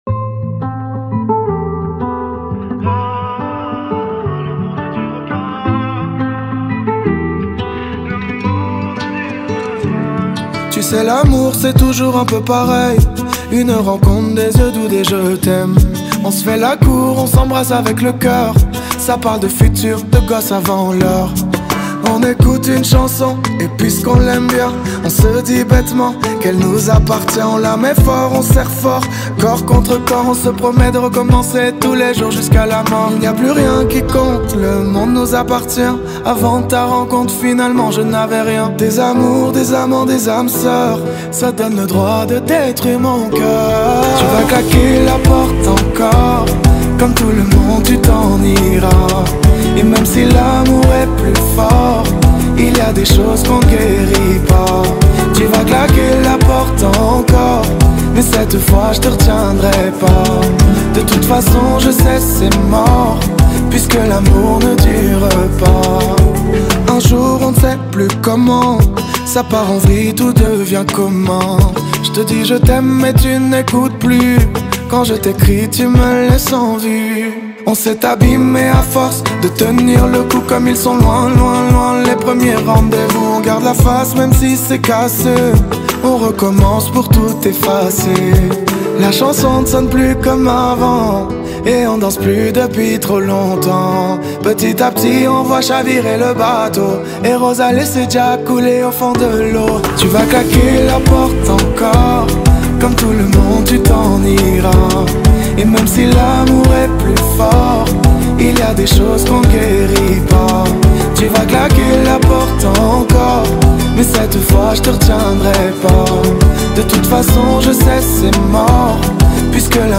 (86 BPM)
Genre: Kizomba Remix